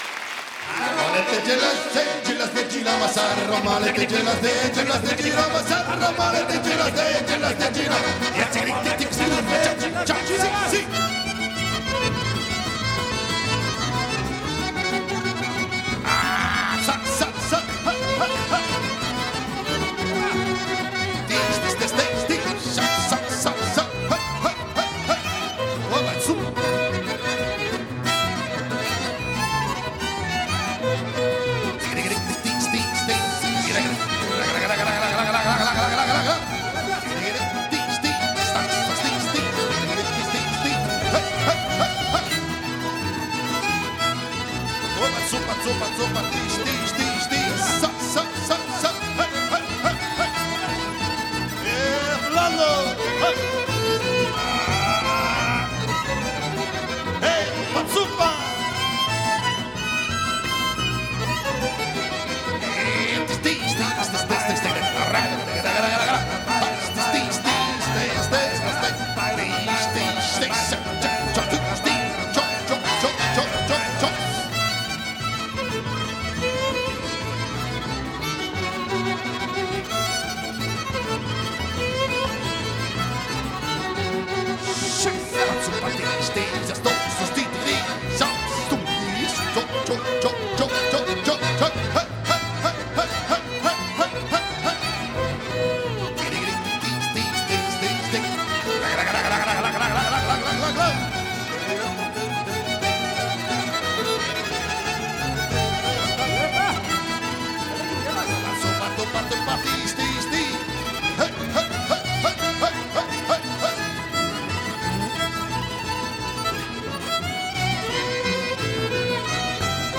其音乐不仅延续了东欧音乐和“茨冈爵士”的神韵，
在他们的那些不同凡响、结构精巧的音乐中，
“既有吉普赛的聪敏机智，也有地中海的热烈亢奋，